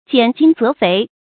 拣精择肥 jiǎn jīng zé féi
拣精择肥发音